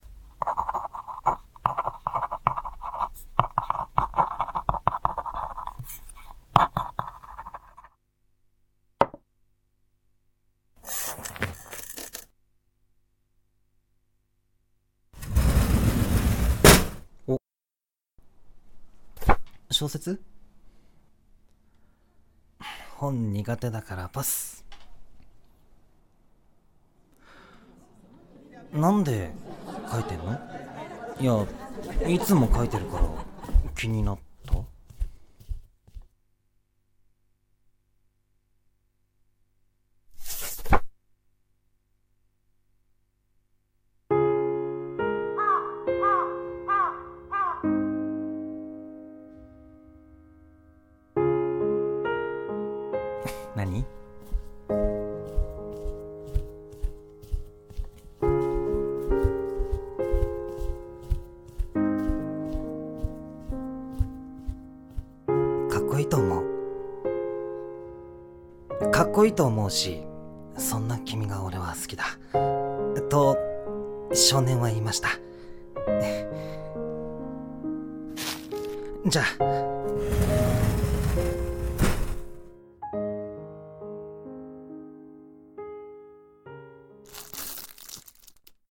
】【二人声劇】ちろるちょこれゐと 〇/あなた ●